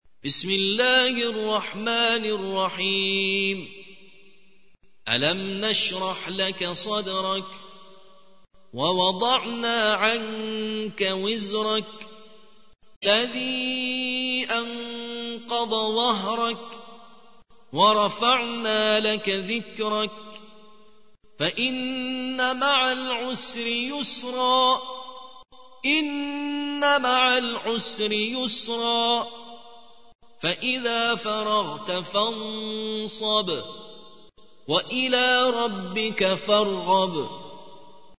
94. سورة الشرح / القارئ